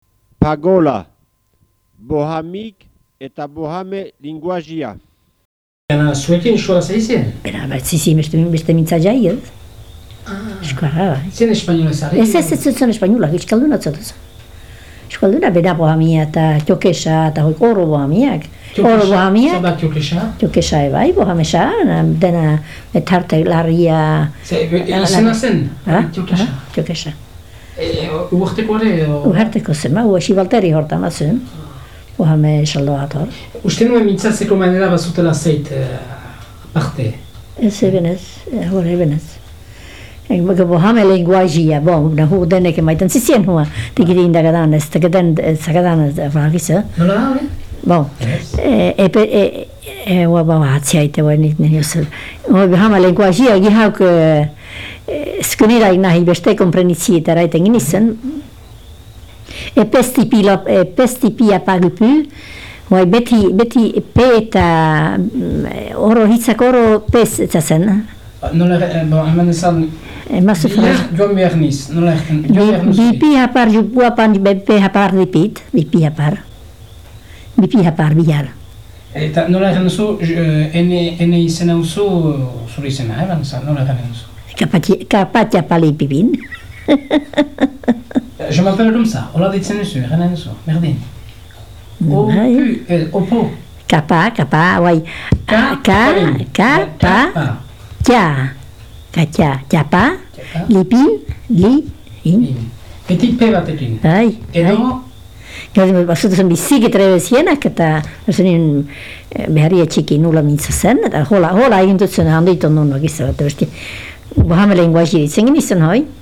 Buhamea zer den azaldu behar zuelarik, lekukoak beste mintzatzeko manera baten berri eman zuen, hizkera horri 'buhame-lenguajia' izena ematen baitio, nahiz nornahik erabil lezakeen, naski buhame izan gabe.